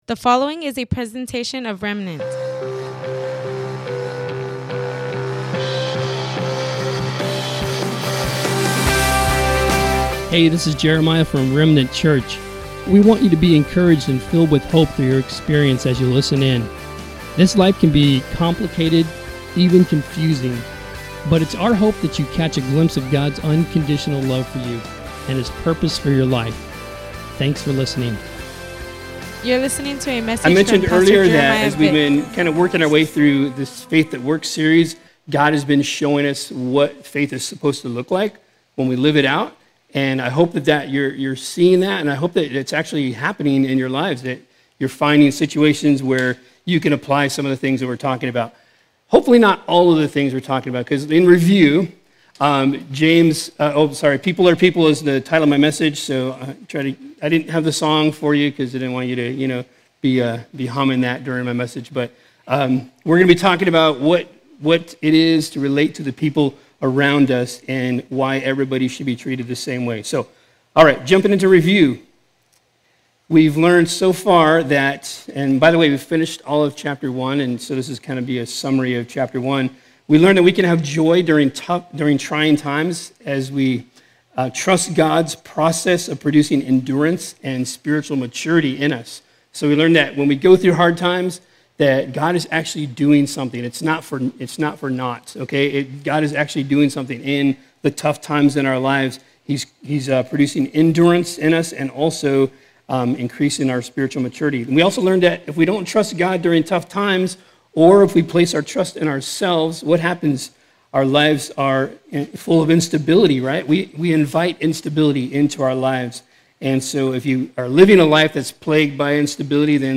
Welcome to the livestream of our worship gathering at Remnant Church in Imperial Valley, CA. Today